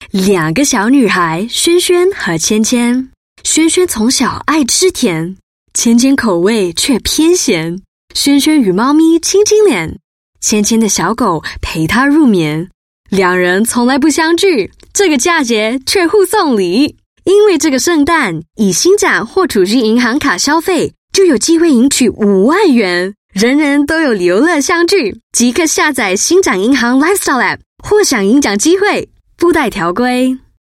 Voice Samples: Voice Demo 01
female